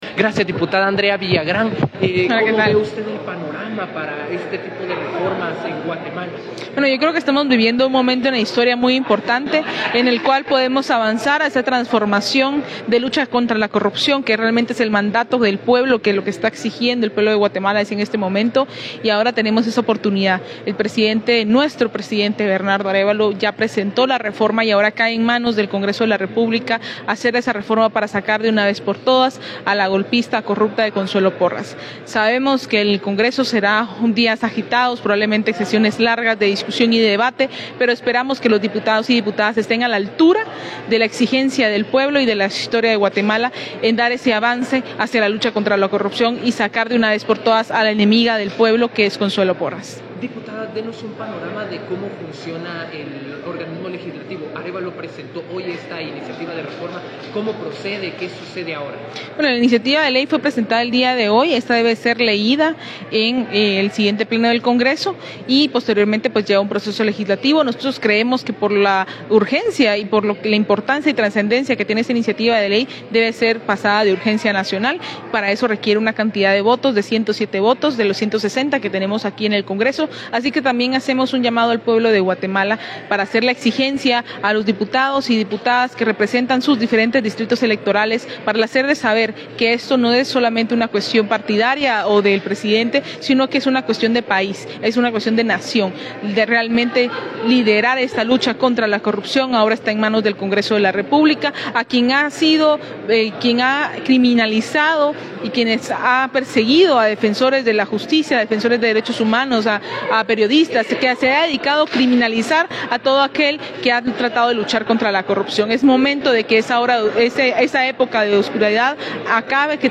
Conferencia de prensa
Declaraciones de la diputada Andrea Villagrán del distrito central.